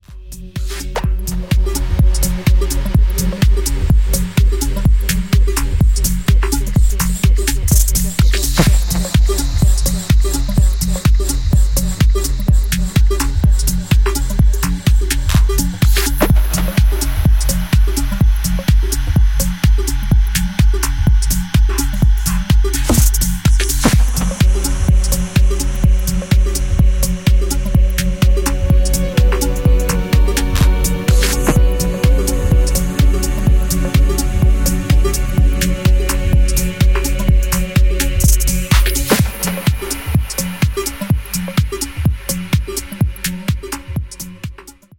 Uptempo and uplifting techno
Solid crowd pleasing techno